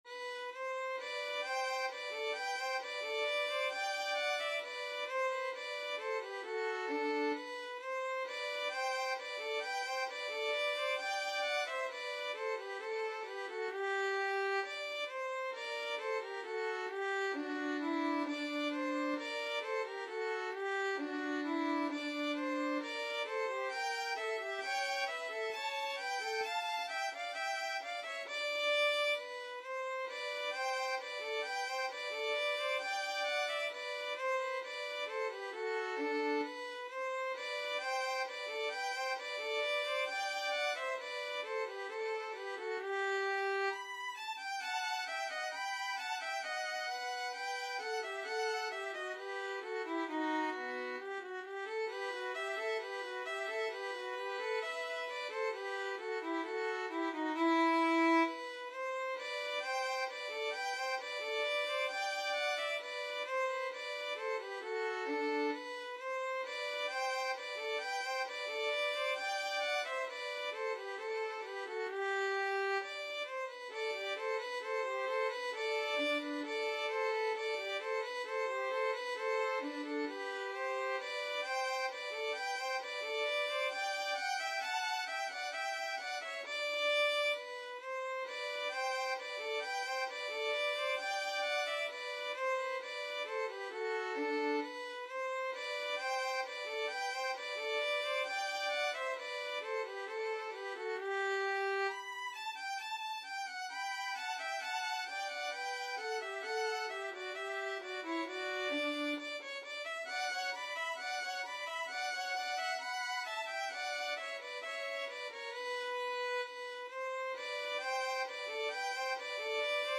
2/2 (View more 2/2 Music)
~ = 100 Allegretto =c.66
Classical (View more Classical Violin Duet Music)